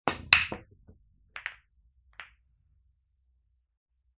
的玉に手玉を当てた時の音。
ビリヤード 着信音